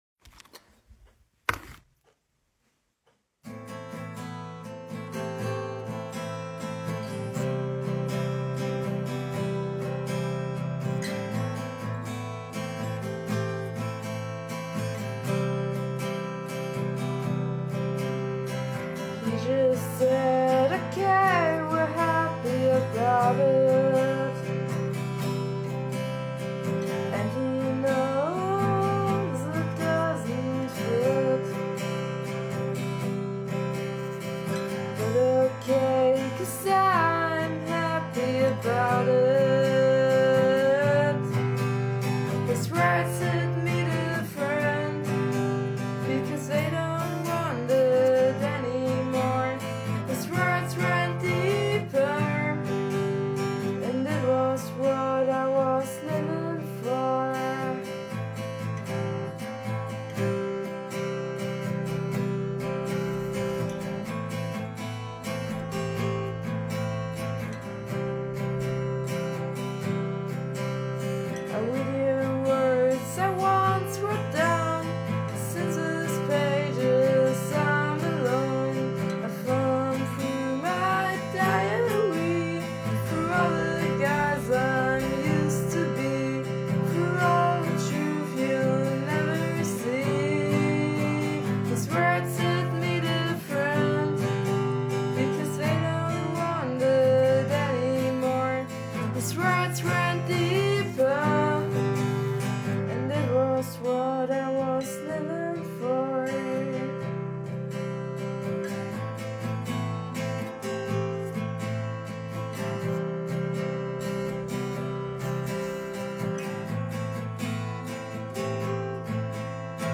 Hey, ich habe einen neuen Song geschrieben und würde mich sehr freuen, wenn ich hier Feedback erhalten könnte:) Es ist eine Demoversion und die Aufnahme wirklich sehr einfach (Akustik Gitarre und Gesang mit dem Handy aufgenommen).